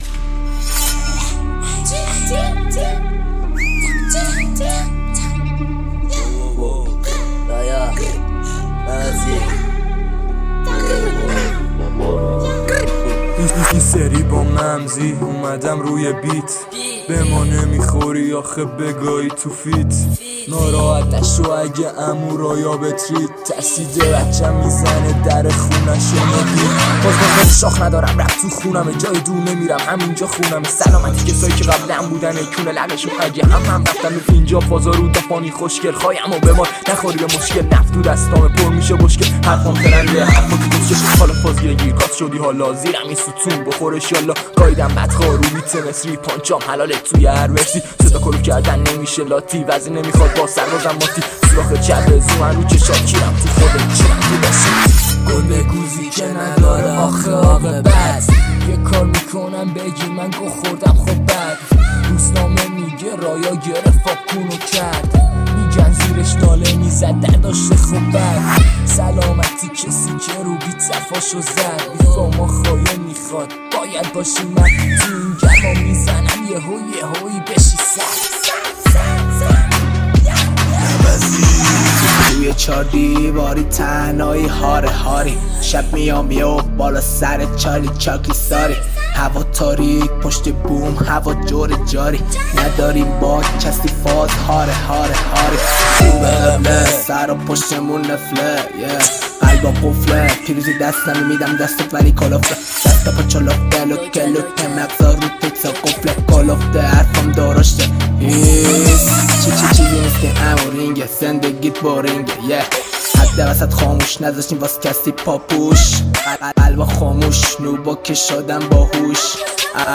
BACK VOCAL